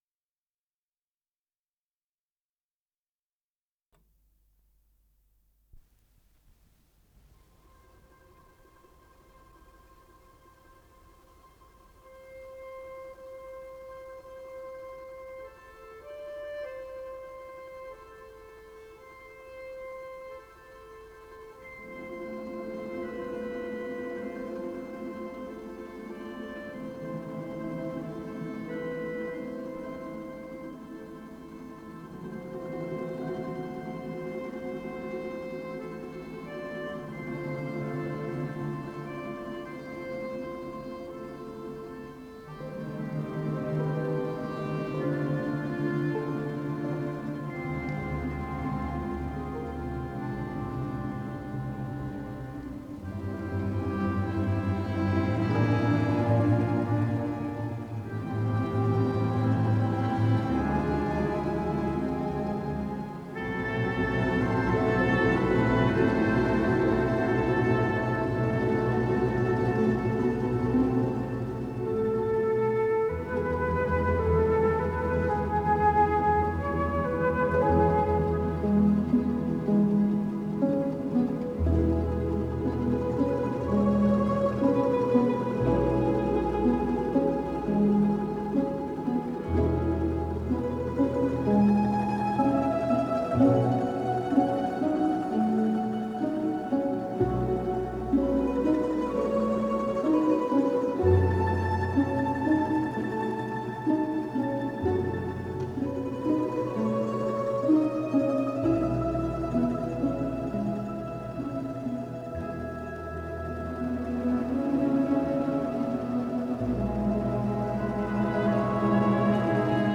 Скорость ленты38 см/с